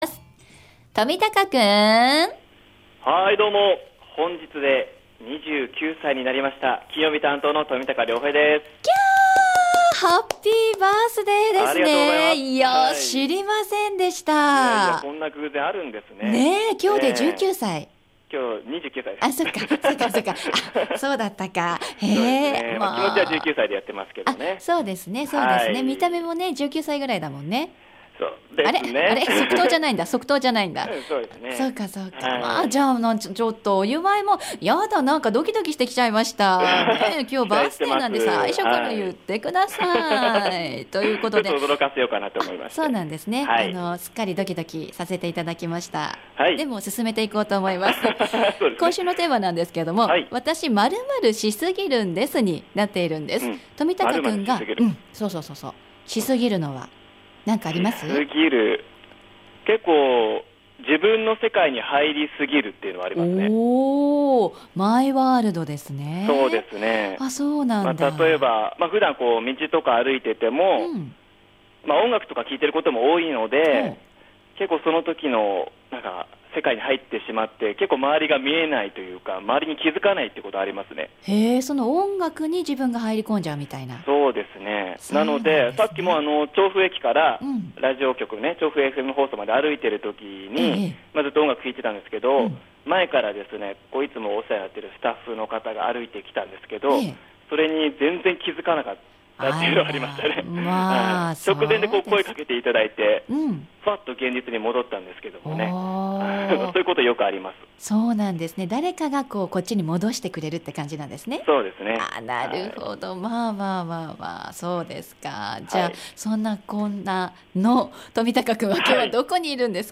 本日、日中は暖かくてまさに春を思わせる陽気！そんな春を恋しがる私が、本日お伺いしたのは京王線国領駅目の前にあるココスクエアビル２Fにございます「調布カルチャーセンター」です♪